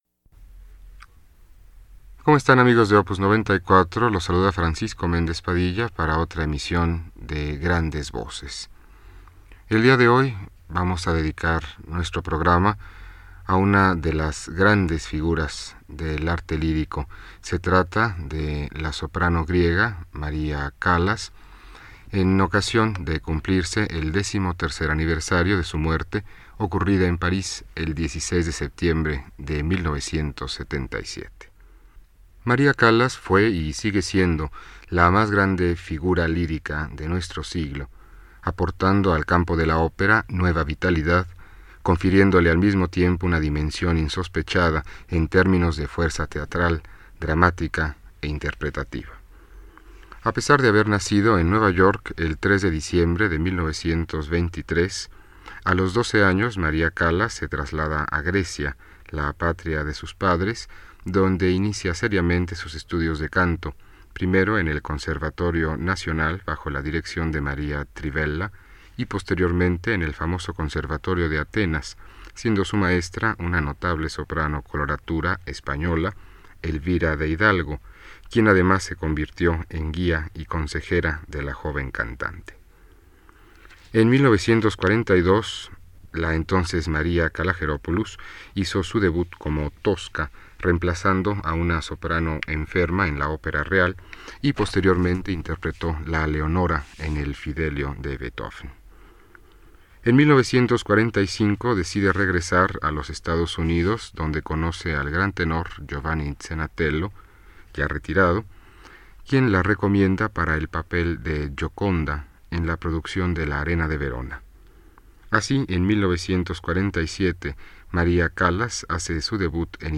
Escucha su sorprendente voz